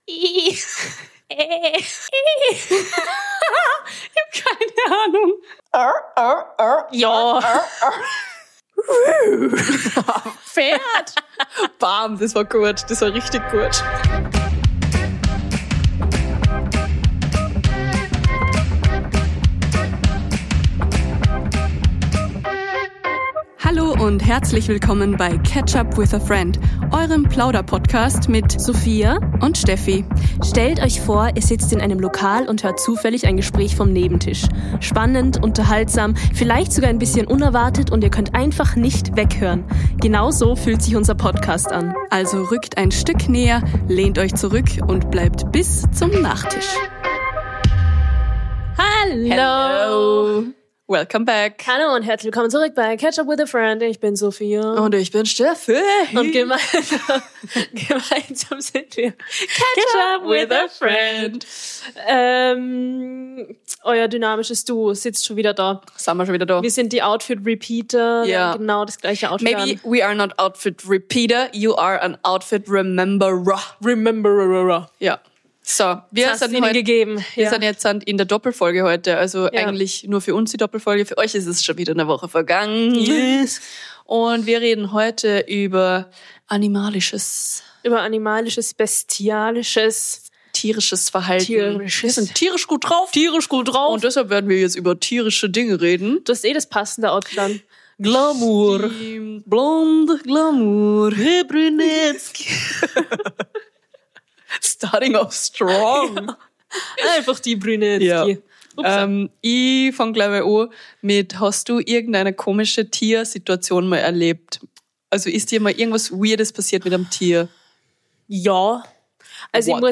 Von Safari-Storys, Nilpferd-Memes und einem Thailand-Spinnendrama bis hin zum großen Tiergeräusche-Quiz - wir imitieren Wildschwein, Delfin & Co. und verlieren uns in Lachflashs über alles, was kriecht, quiekt und grunzt.